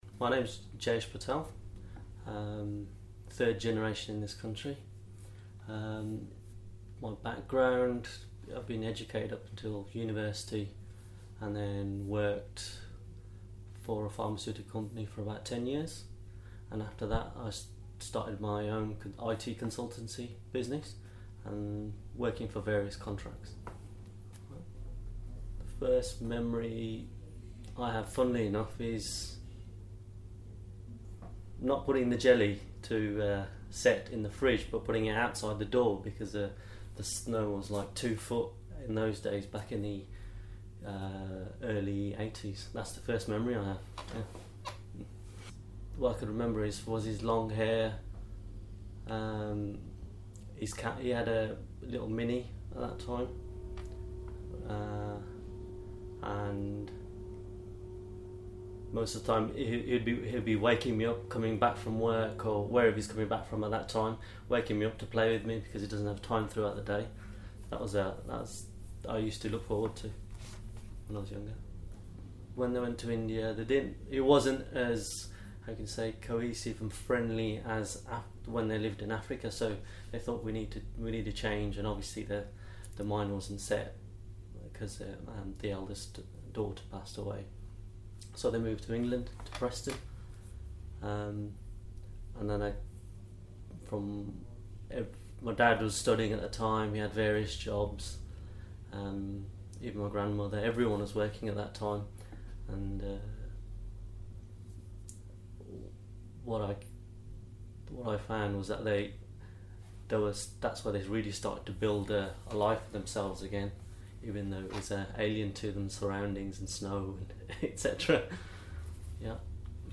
Interview with South Asian Father and Son about migration and his life in Birmingham 2013.